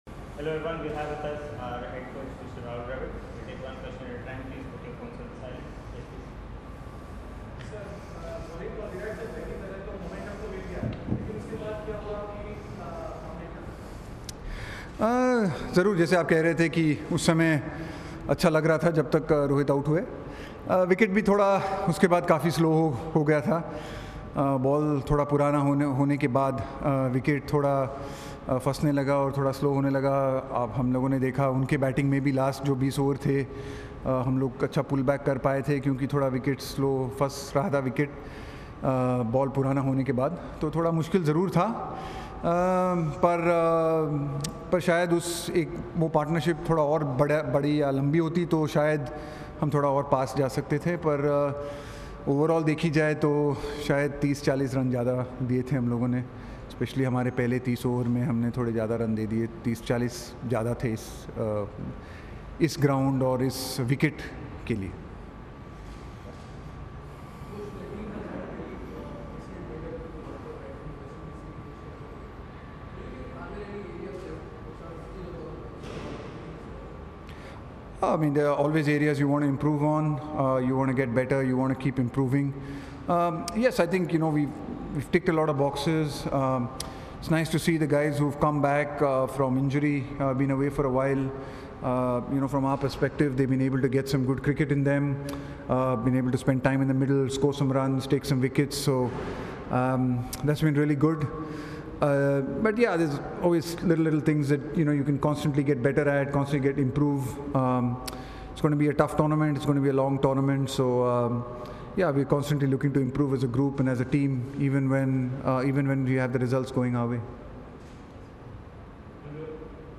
Rahul Dravid addressed a press conference after the third IDFC First Bank ODI Series against Australia
Rahul Dravid, Head Coach, Indian Cricket Team addressed a press conference on Wednesday after the third IDFC First Bank ODI Series against Australia.